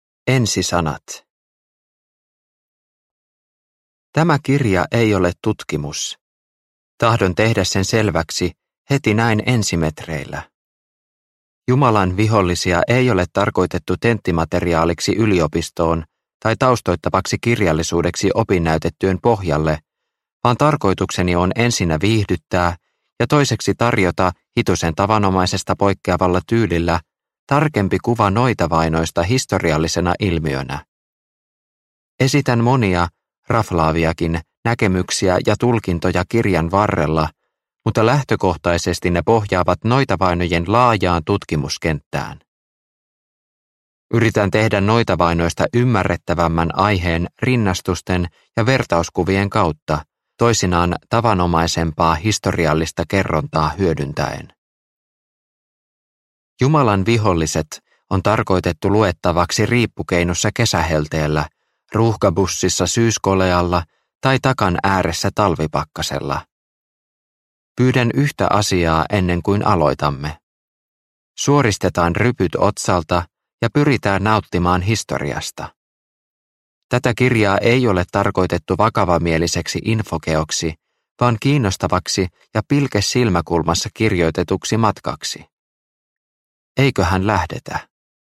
Jumalan viholliset – Ljudbok – Laddas ner